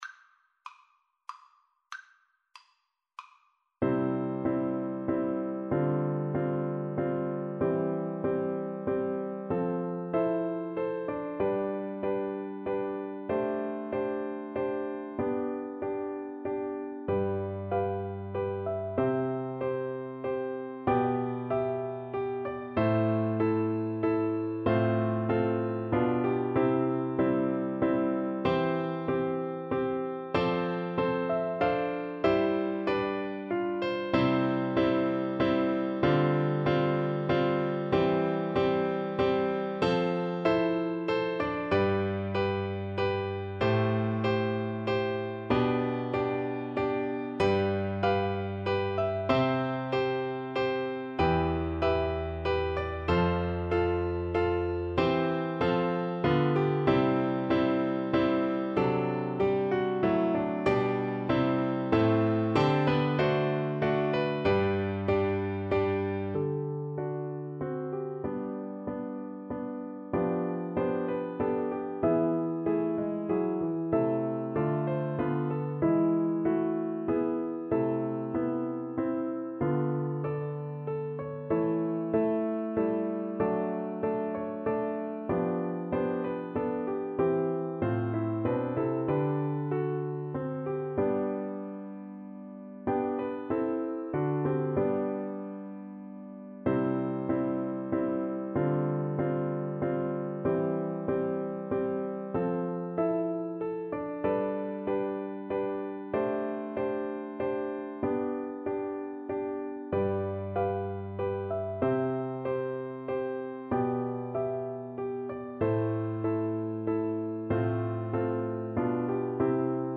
Play (or use space bar on your keyboard) Pause Music Playalong - Piano Accompaniment Playalong Band Accompaniment not yet available reset tempo print settings full screen
French Horn
C major (Sounding Pitch) G major (French Horn in F) (View more C major Music for French Horn )
=95 Andante
3/4 (View more 3/4 Music)
Classical (View more Classical French Horn Music)